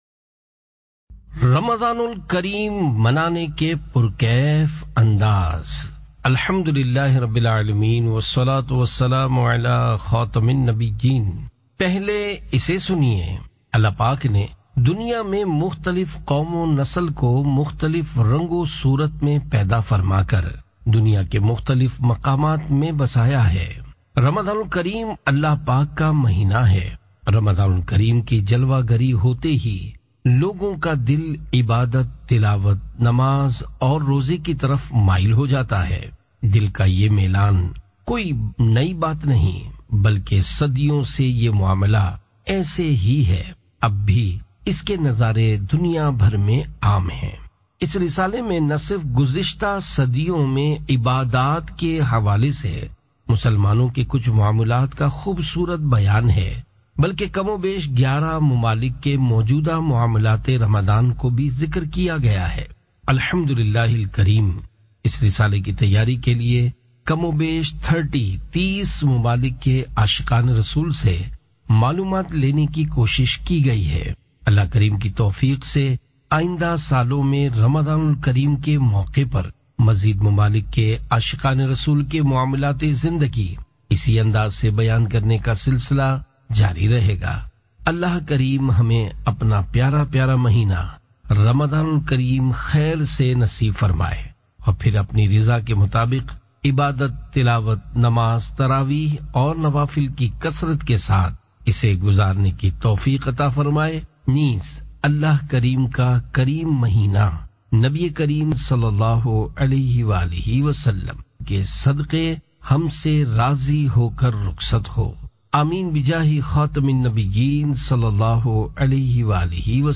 Audiobook - Ramazan ul Kareem Manane Ke Pur Kaif Andaz (Urdu)